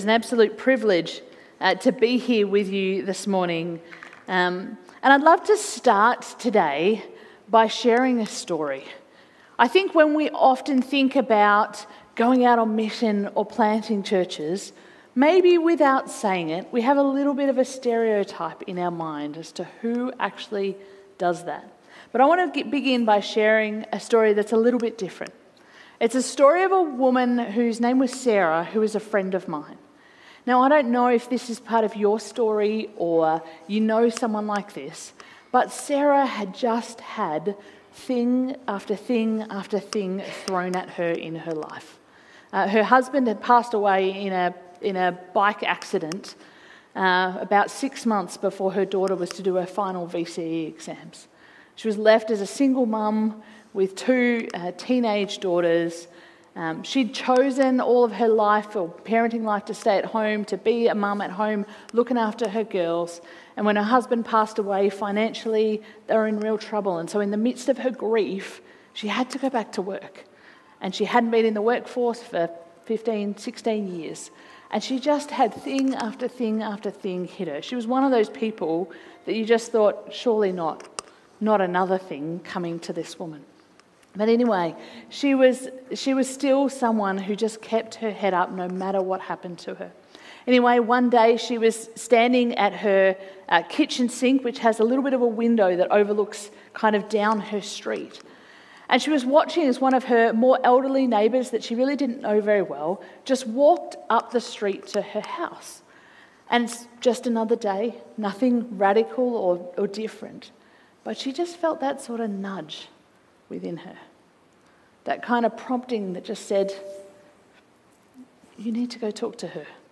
Sermon on Acts 1 - Chosen, Sent, and Empowered